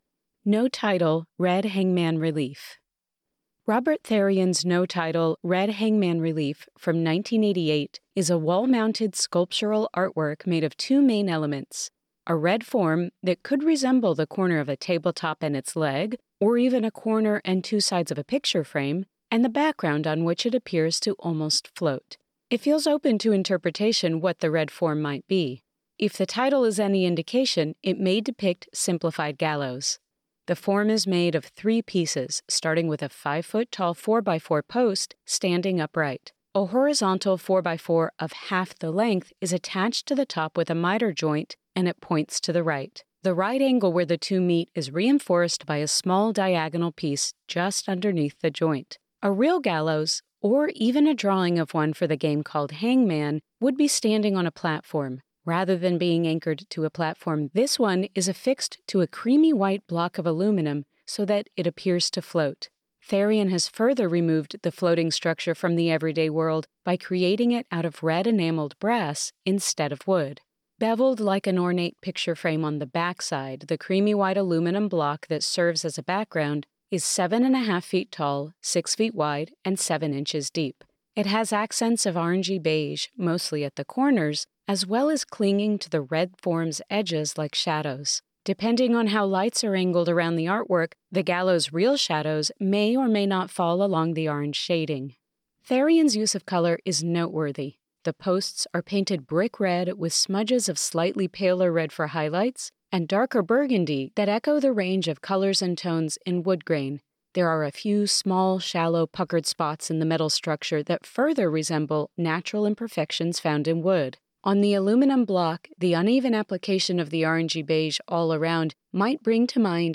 Audio Description (02:29)